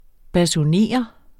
Udtale [ basuˈneˀʌ ]